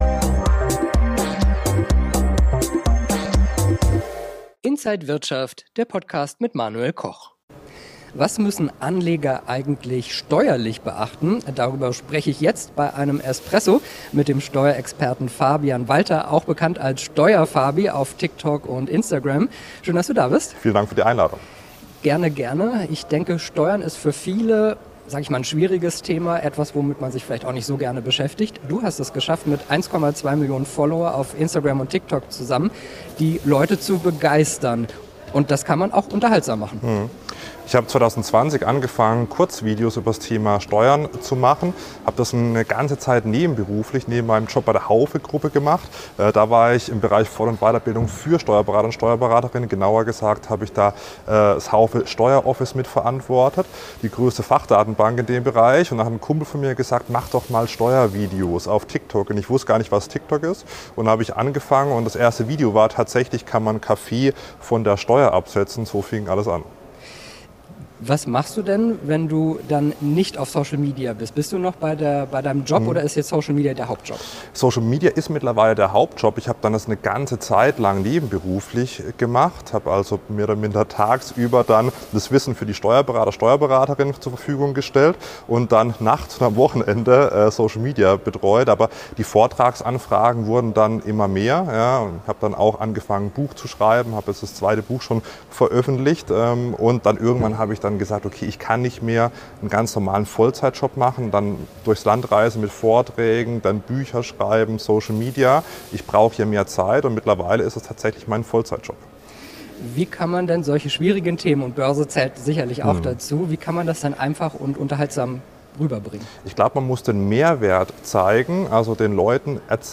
Alle Details im Interview von Inside